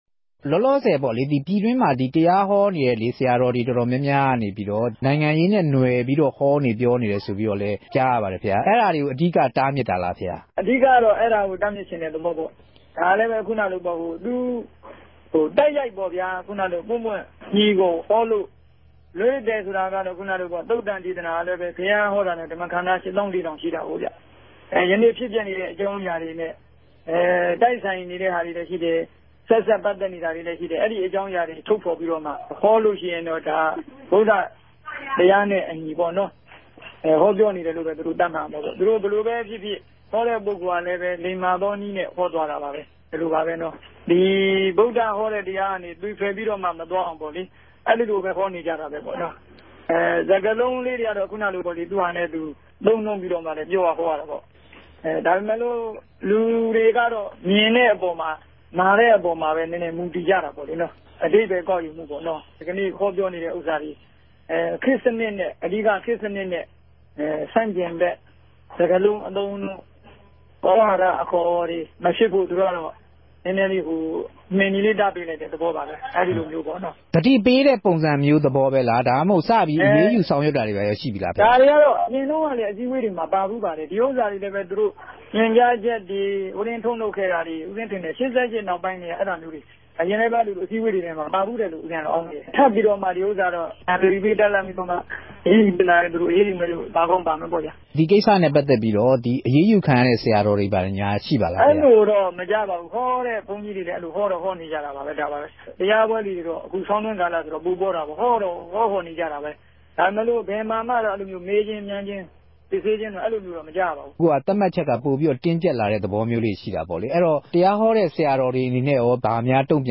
လ္တေွာက်ထားမေးူမန်းခဵက်။